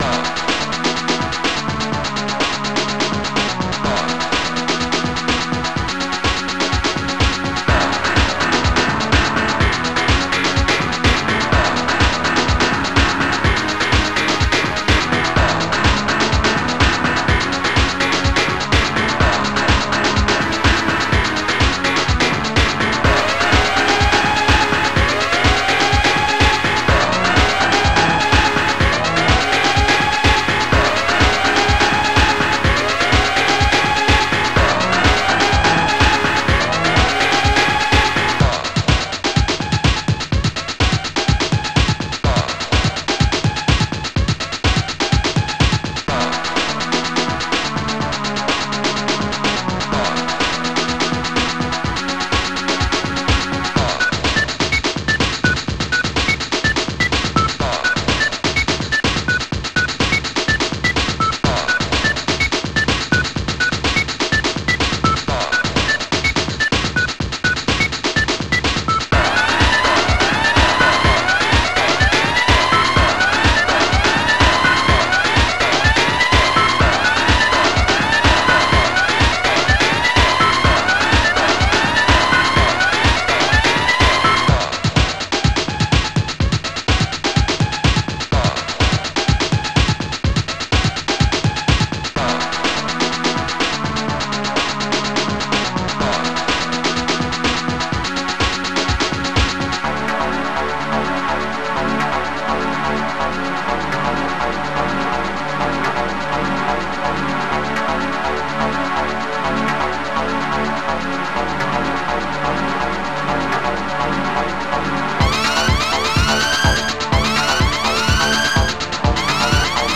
hammer chord
snare
siren
acid bleep
synth1
kick bass
and a rather groovy